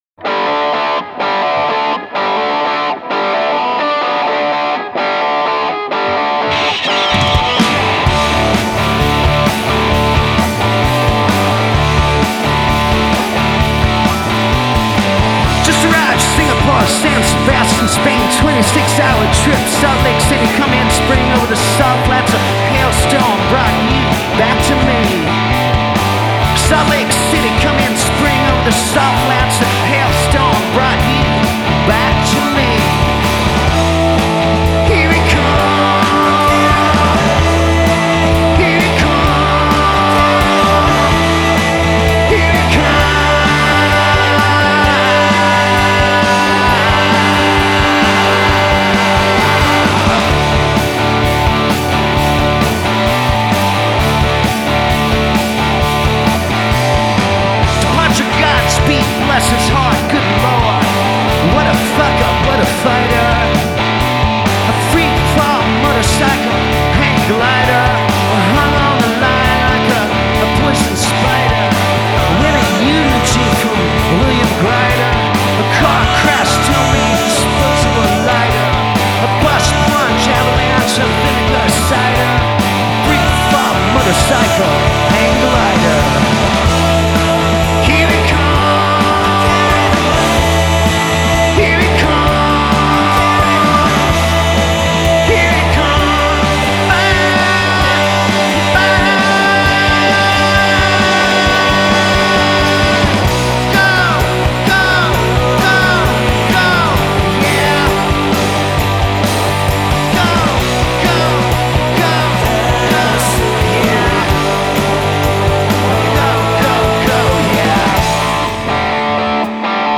grooving on a turned-up-to-11 Les Paul riff